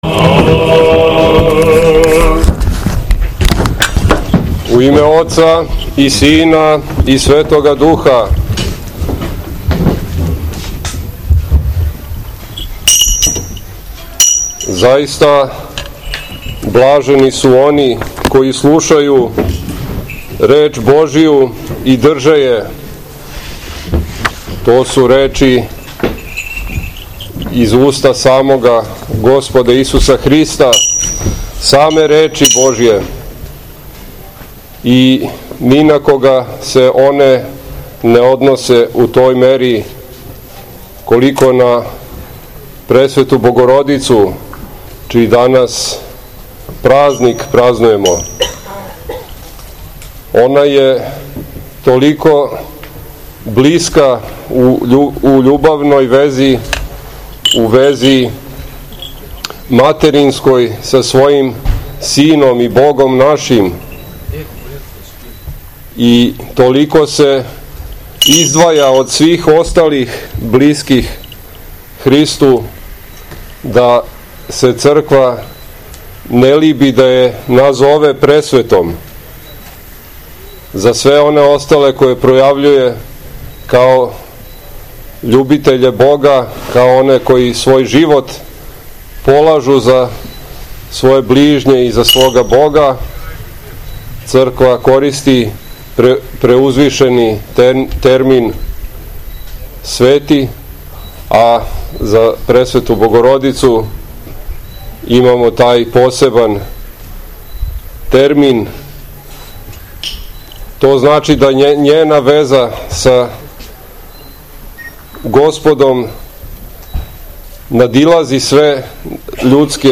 Беседа Његовог Преосвештенства Епископа ваљевског Господина Исихија